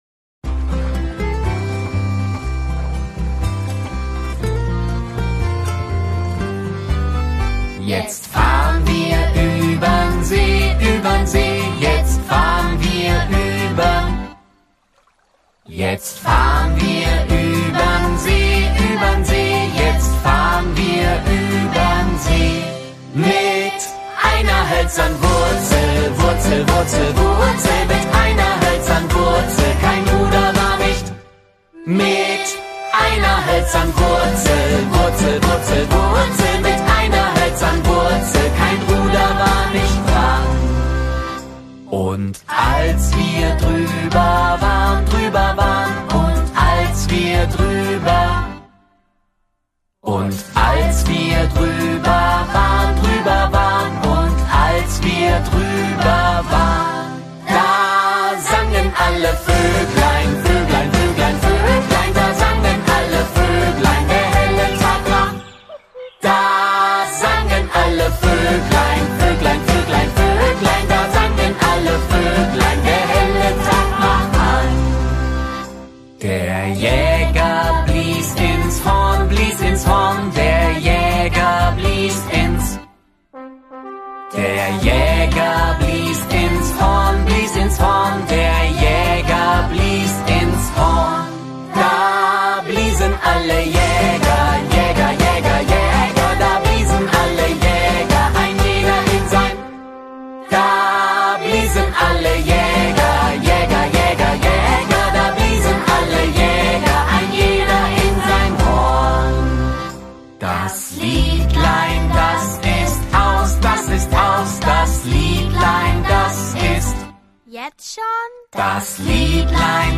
Kinderlied2.mp3